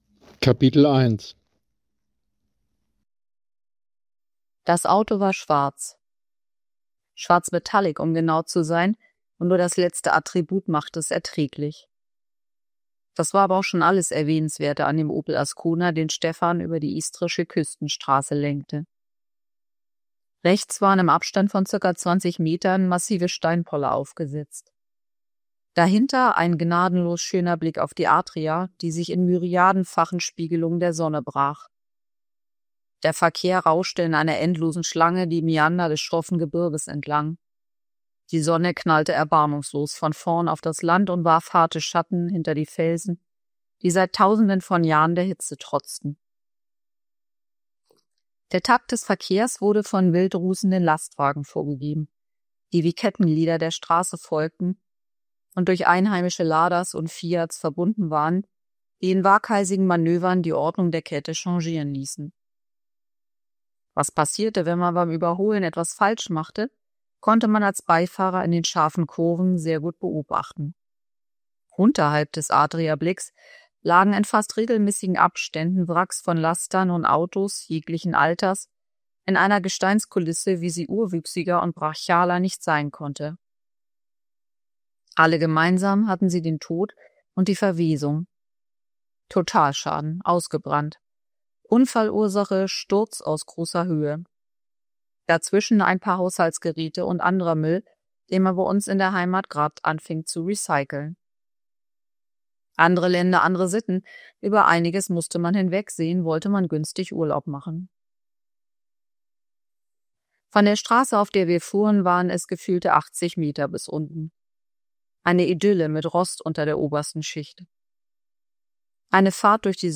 Homepage Leseprobe.mp3